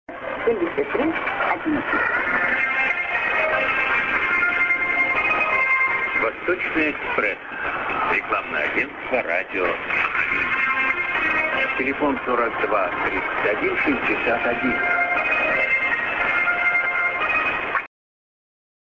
->ID(man)->　USB R.Sakharinsk(Radio Rossii)